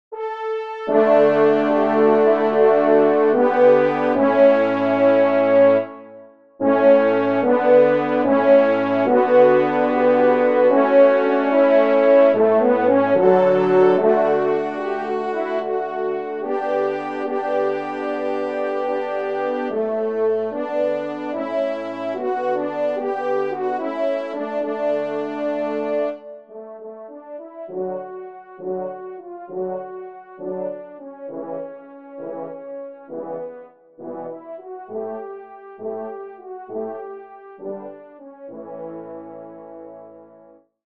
Genre : Divertissement pour Trompes ou Cors
Pupitre 4° Cor